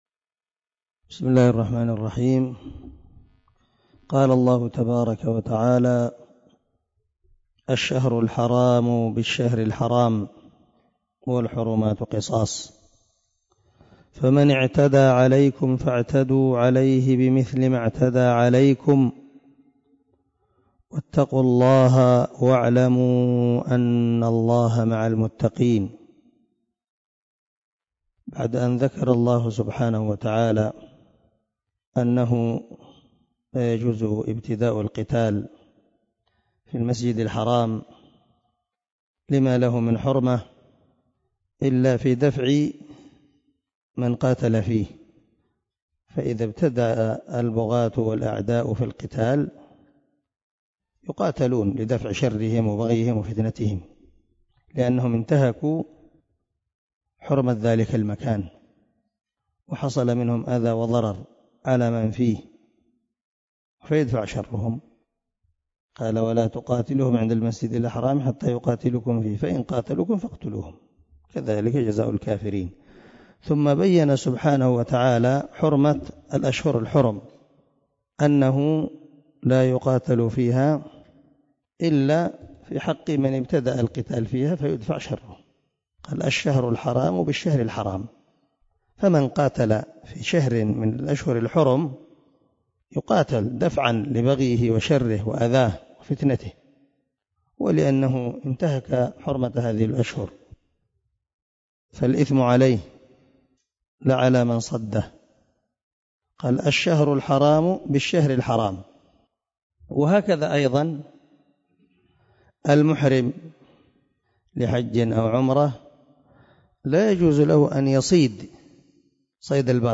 089الدرس 79 تفسير آية ( 194 ) من سورة البقرة من تفسير القران الكريم مع قراءة لتفسير السعدي
دار الحديث- المَحاوِلة- الصبيحة.